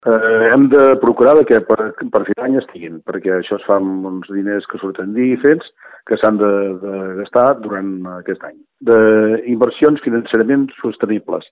Segons el regidor d’Obra Pública, Lluís Ros, la recuperació econòmica ha influït en aquest fet: